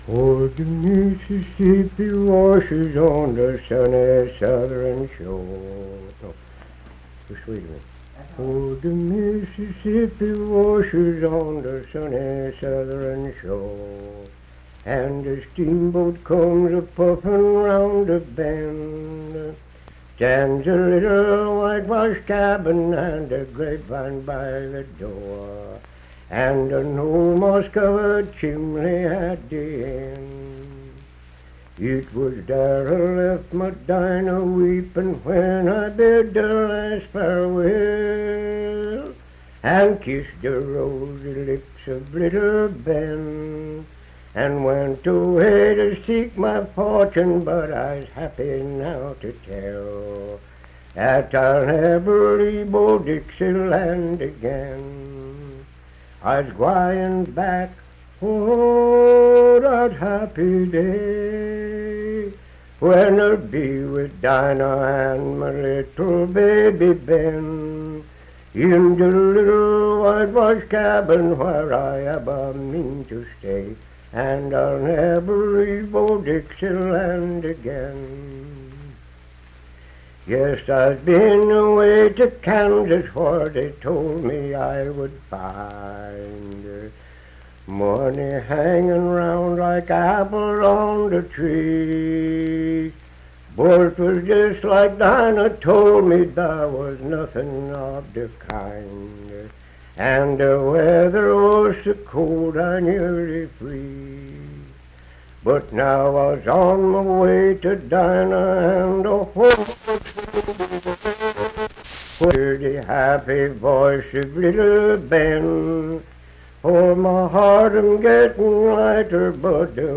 Unaccompanied vocal music performance
Verse-refrain 6d(8-12w/R).
Miscellaneous--Musical
Voice (sung)